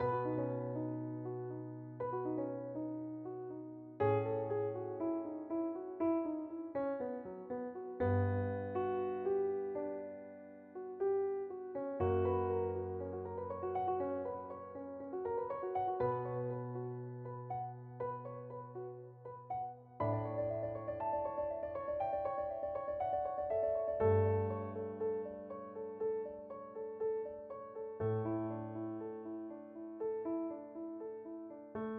Tag: 70 bpm Ambient Loops Guitar Electric Loops 4.61 MB wav Key : Unknown